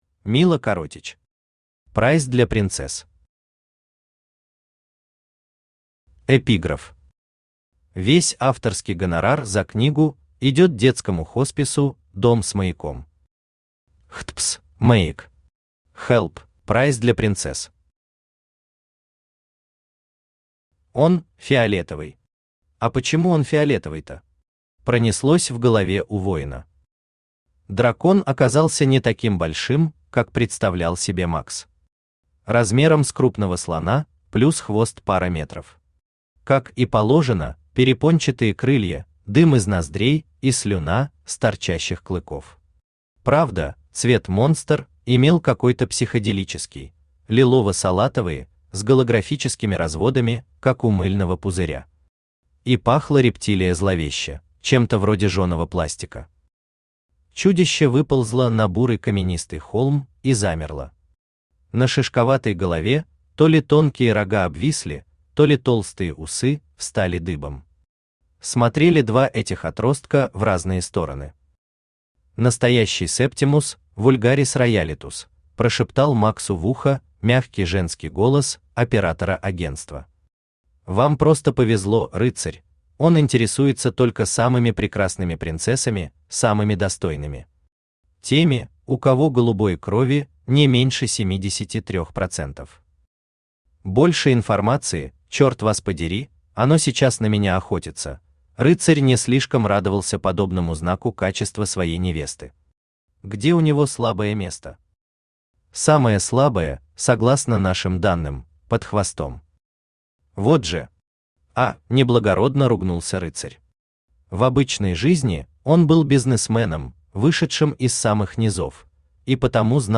Аудиокнига Прайс для принцесс | Библиотека аудиокниг